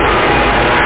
Amiga 8-bit Sampled Voice
1 channel
EngineWhine.mp3